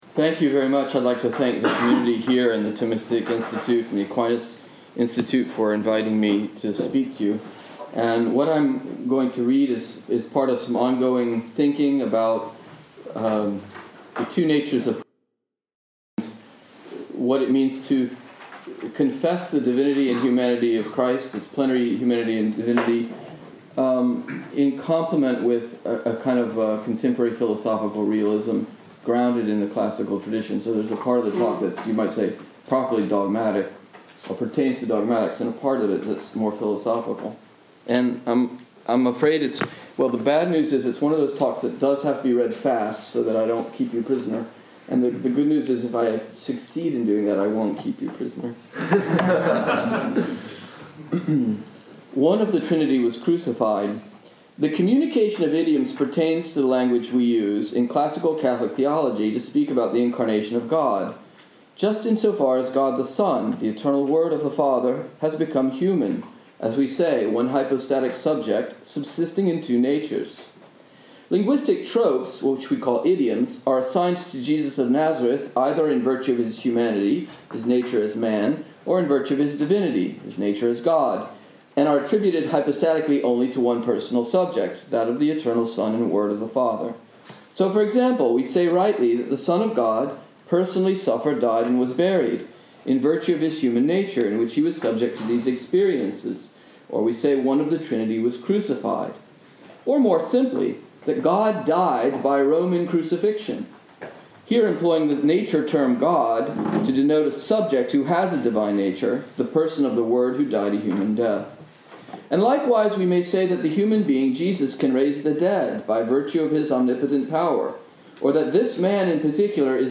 Summary This lecture